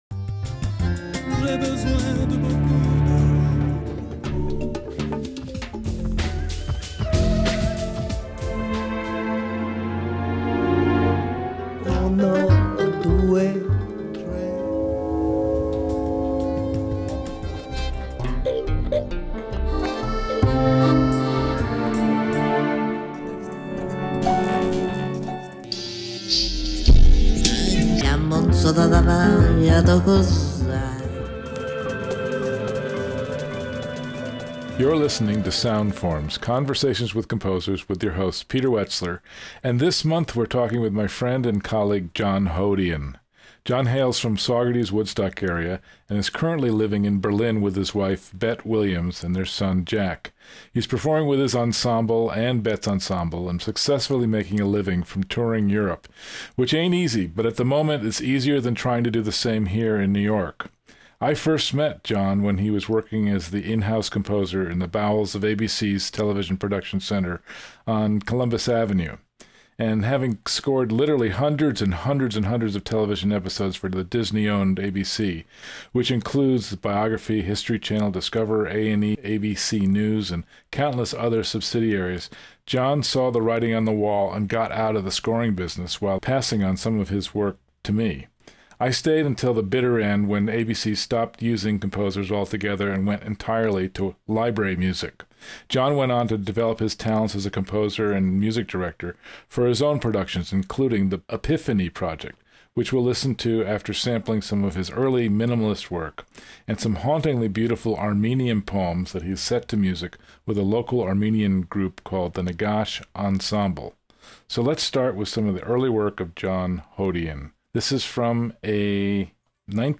Sound Forms: Conversations with Composers